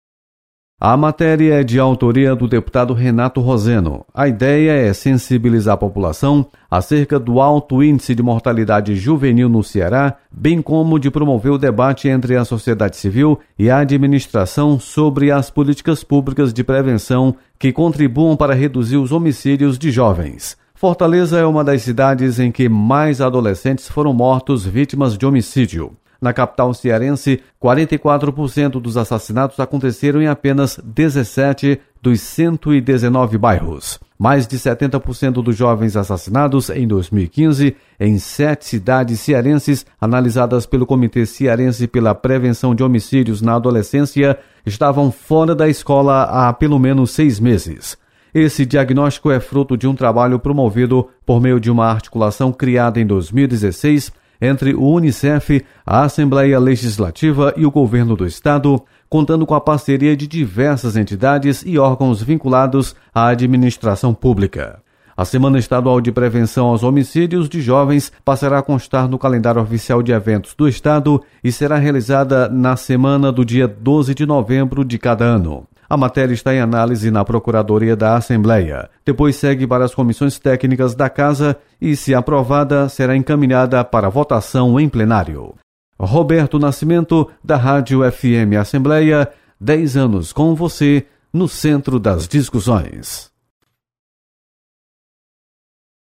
Projeto institui a Semana Estadual de Prevenção aos Homicídios de Jovens. Repórter